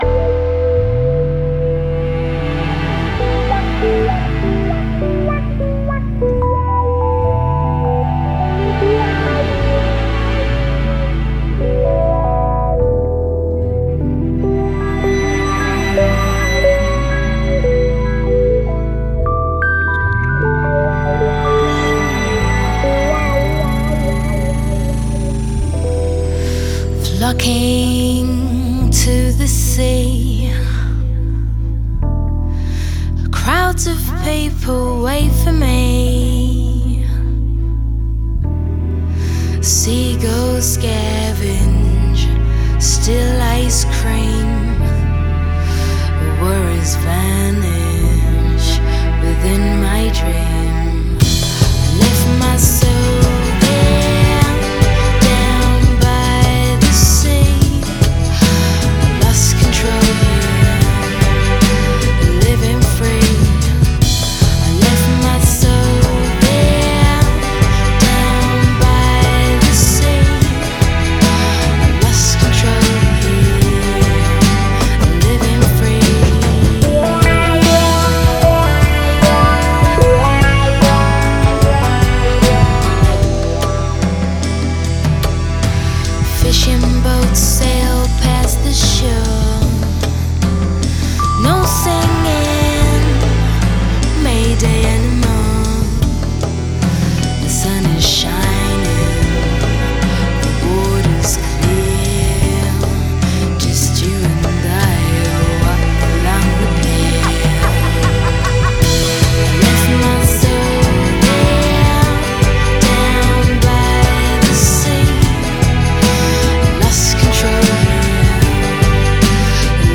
Trip Hop, Downtempo, Pop, Rock